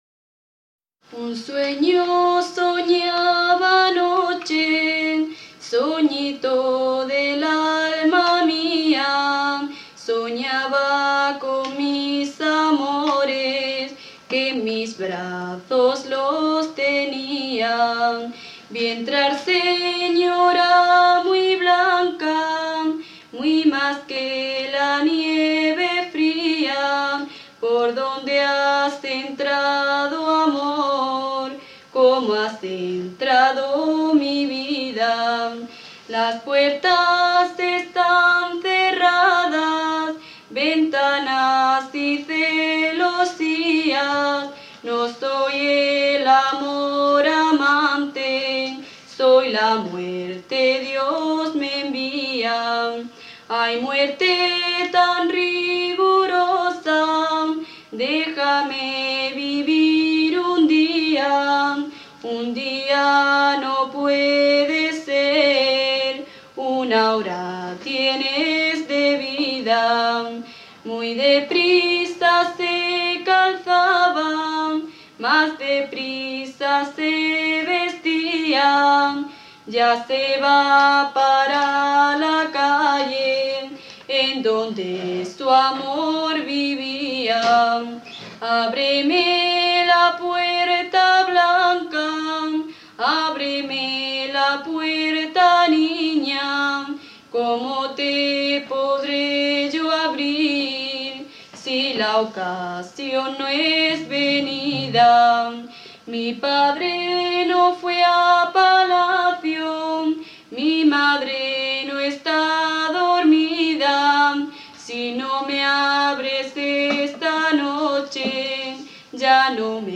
Materia / geográfico / evento: Romances Icono con lupa
Secciones - Biblioteca de Voces - Cultura oral